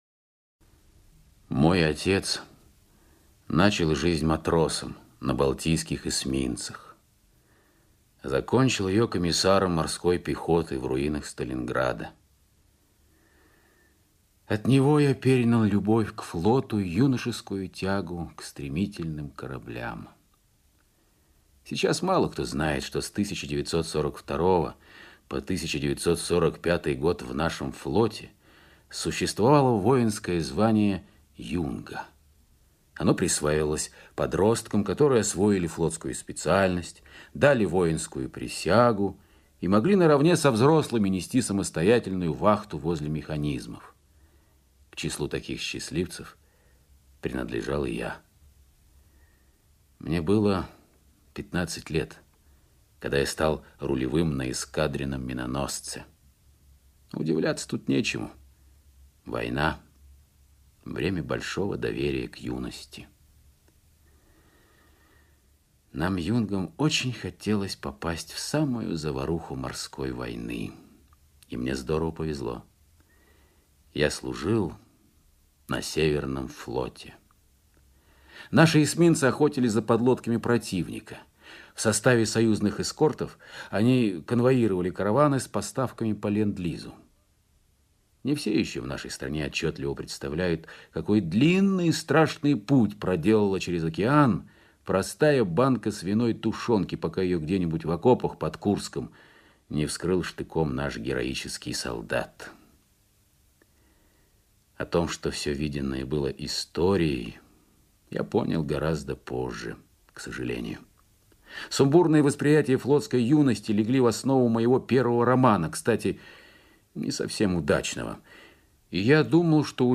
Аудиокнига Реквием каравану PQ-17
Качество озвучивания весьма высокое.